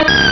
sovereignx/sound/direct_sound_samples/cries/igglybuff.aif at master